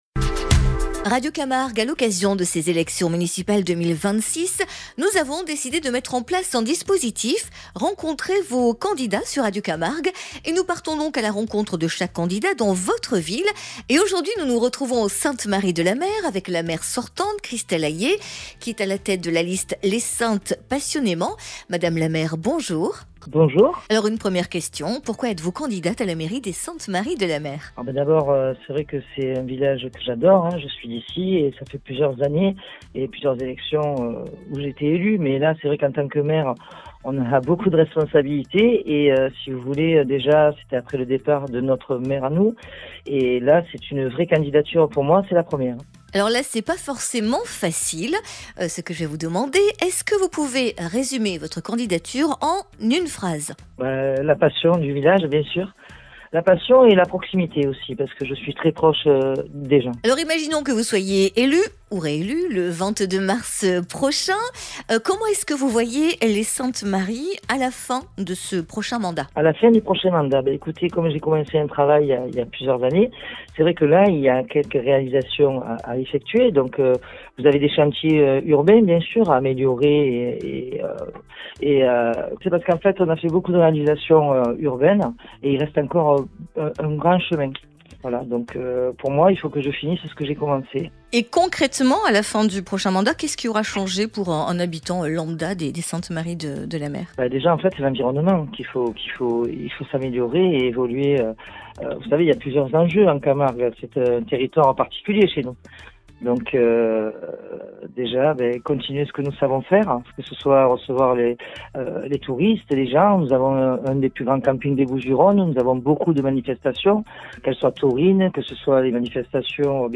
Municipales 2026 : entretien avec Christelle Aillet